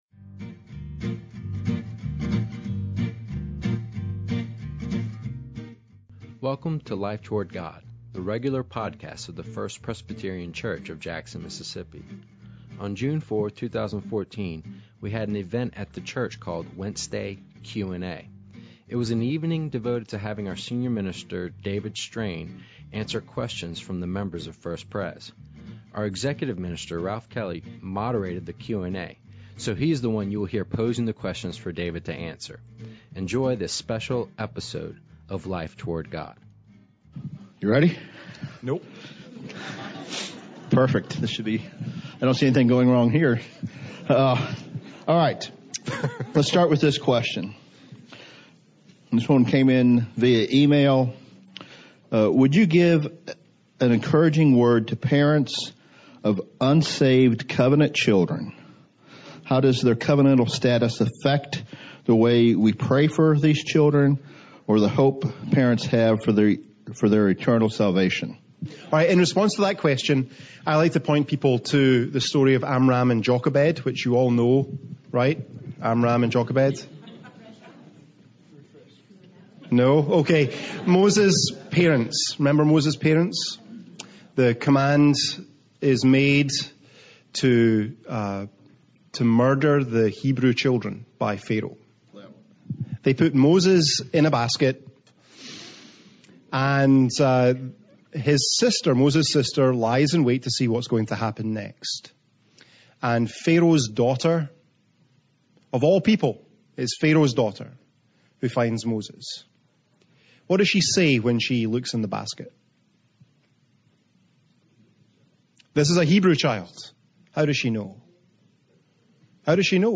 Wed_QnA_Life_Toward_God.mp3